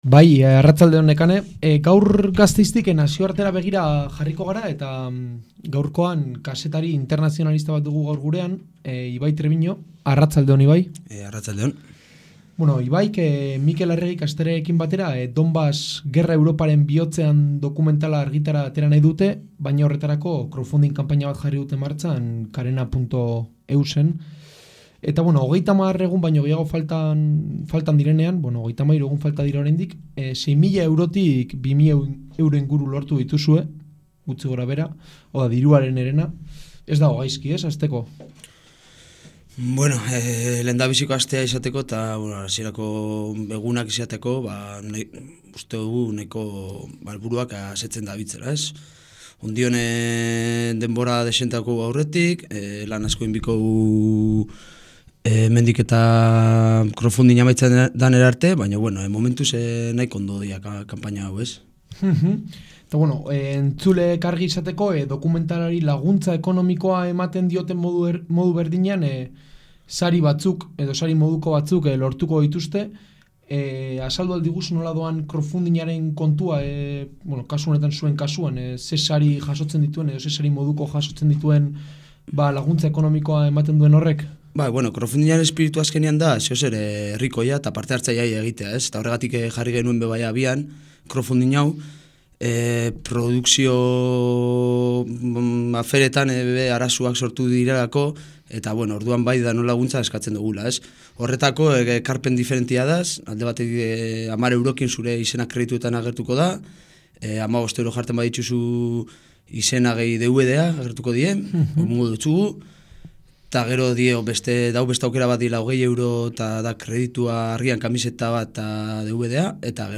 elkarrizketa, Halabedi irratian.